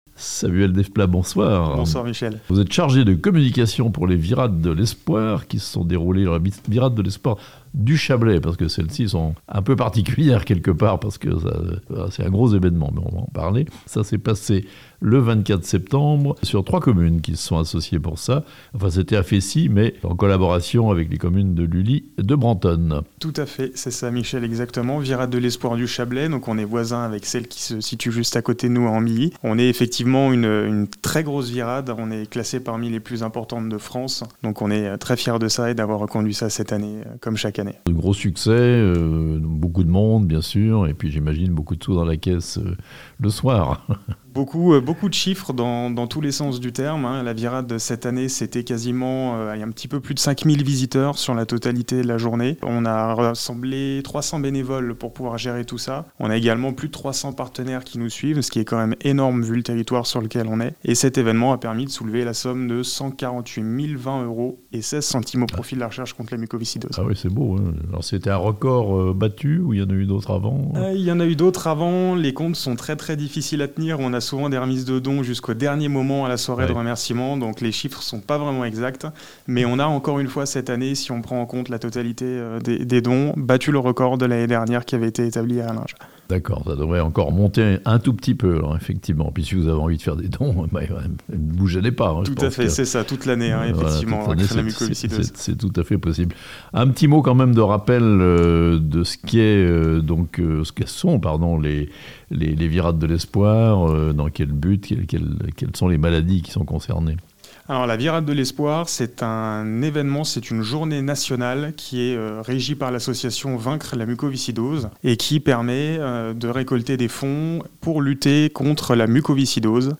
Réussite totale pour les "Virades de l'Espoir" en Chablais (interview)